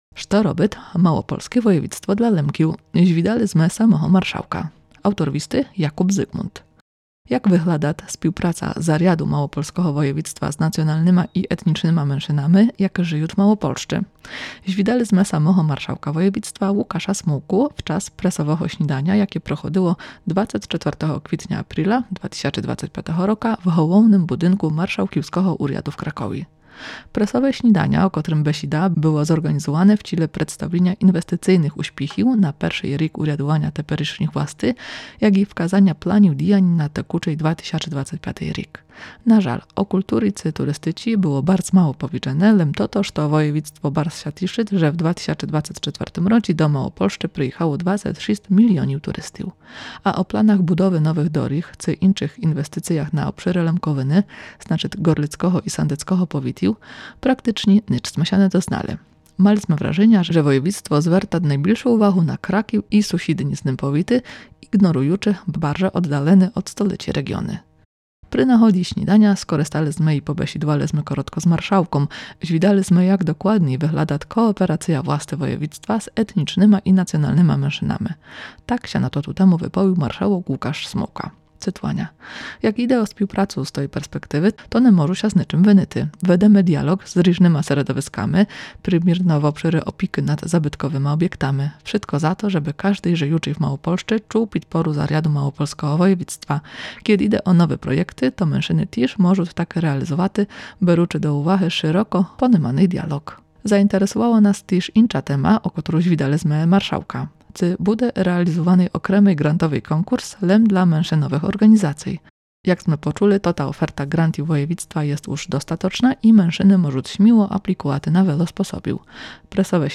Звідали сме самого Маршалка Воєвідства – Лукаша Смулку – вчас пресового сніданя, якє проходило 24. квітня/апріля 2025 р. в головным будинку Маршалківского Уряду в Кракові.
Так ся на тоту тему выповіл маршалок Лукаш Смулка: